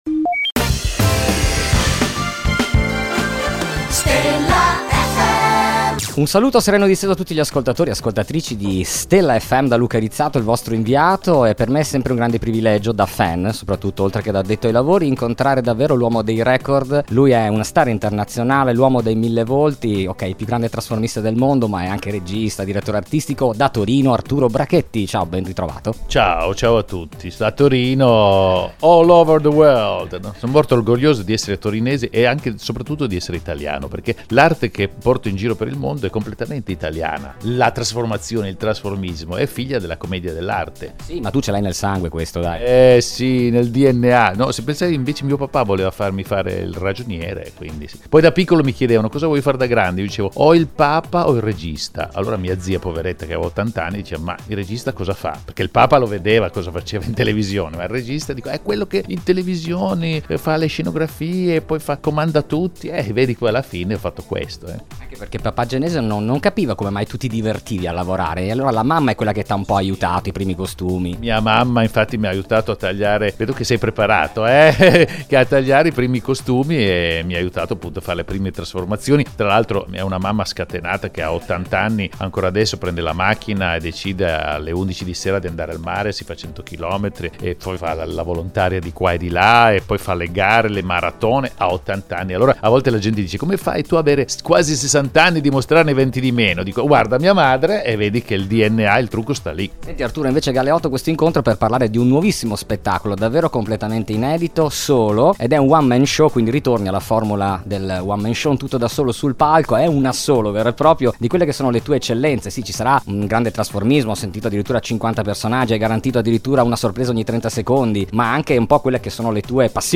Intervista Arturo Brachetti | Stella FM